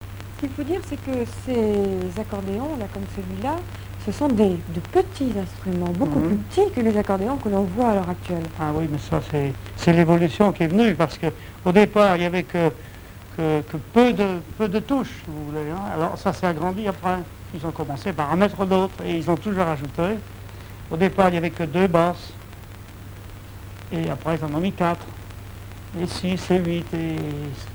accordéon(s), accordéoniste
Musique, bal, émission de radio
Témoignage